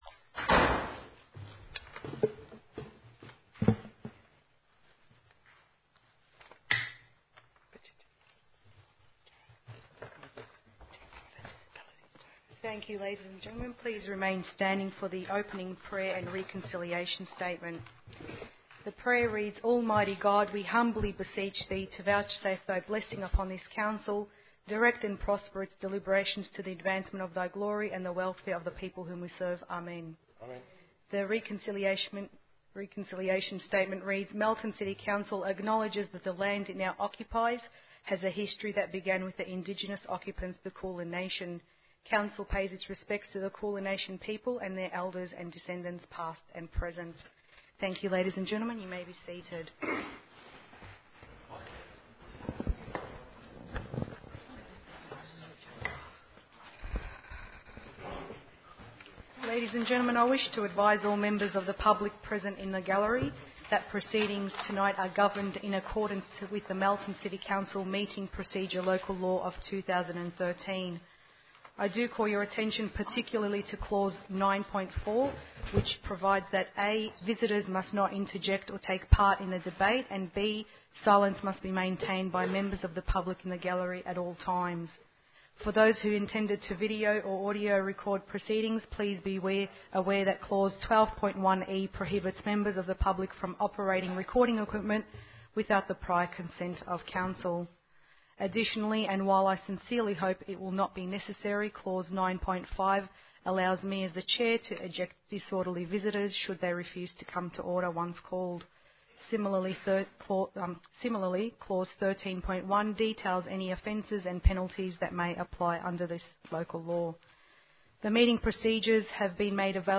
17_sept_council_meeting.mp3